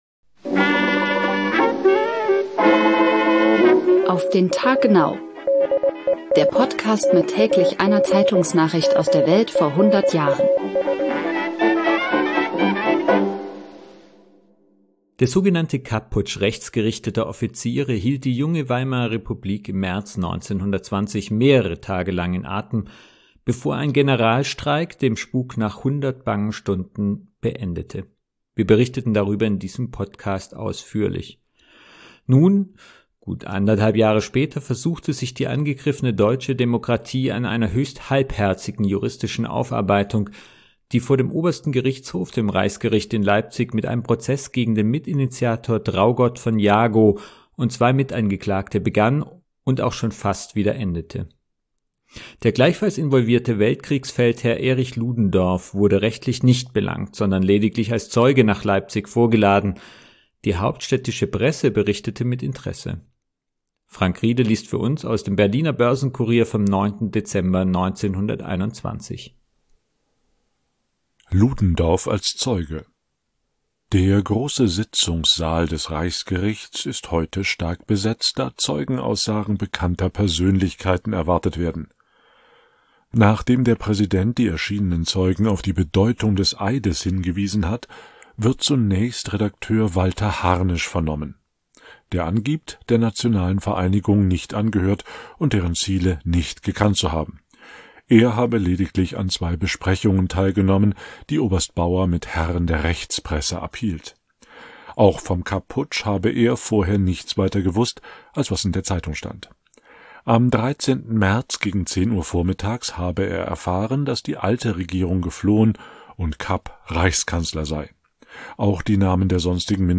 liest für uns aus dem Berliner Börsen-Courier vom 9.